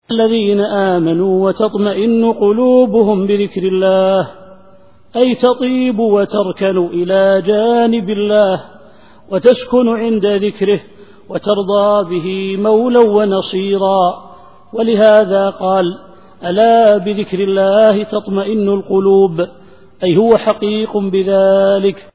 التفسير الصوتي [الرعد / 28]